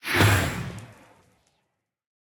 Minecraft Version Minecraft Version snapshot Latest Release | Latest Snapshot snapshot / assets / minecraft / sounds / block / trial_spawner / spawn3.ogg Compare With Compare With Latest Release | Latest Snapshot